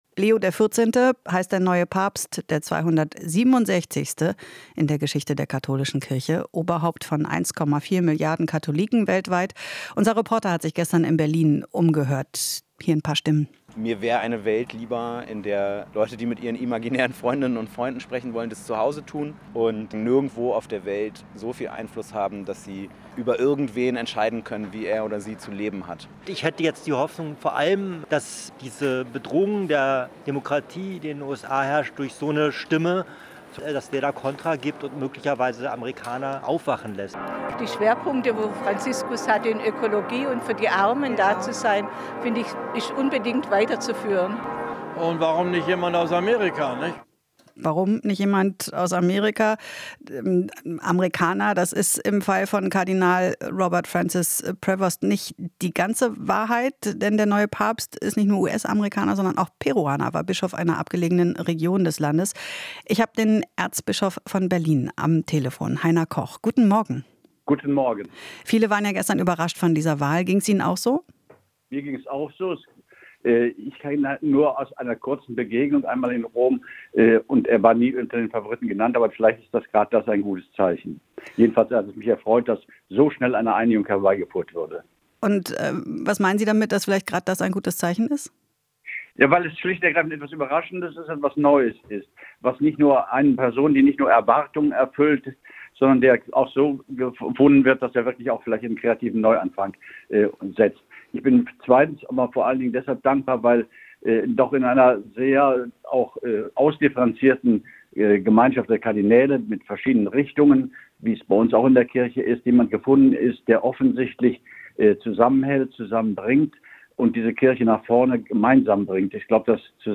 Interview - Erzbischof Koch: Leo XIV. ist jemand, der zusammenbringt